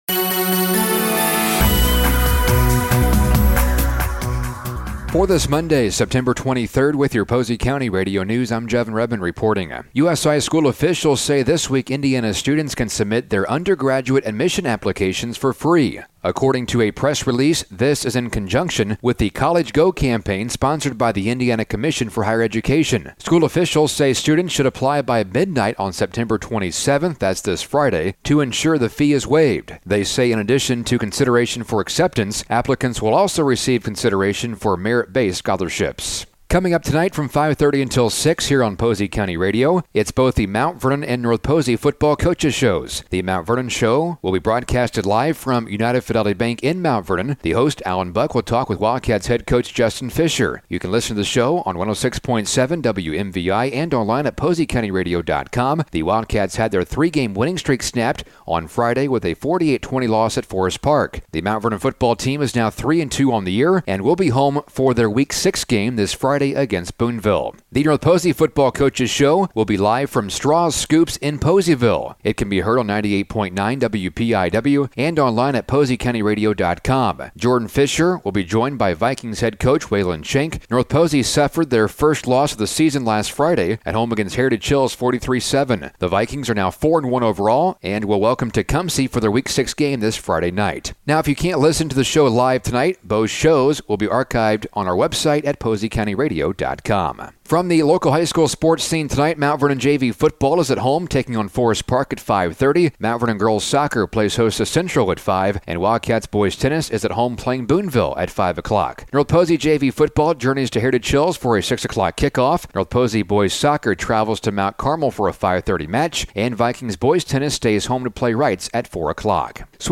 Local News: Monday September 23rd 2024